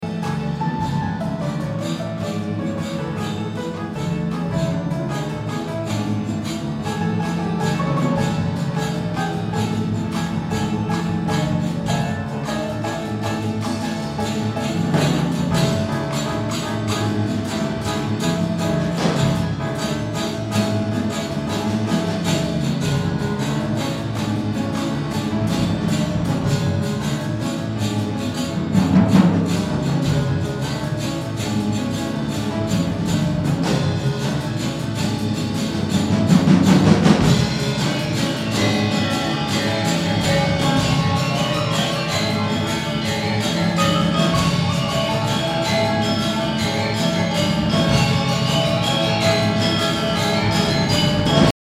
Such performances may simply use the Balinese instruments for their particular timbre, or they may also incorporate other elements of Balinese music.
Reng Gam-Jazz , which uses a jazz rhythm section along with Balinese instruments, in a form that includes jazz-style improvisation as well as an eight-beat Balinese-style cycle.